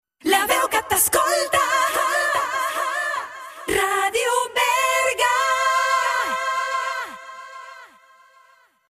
Indicatiu curt, només amb veu.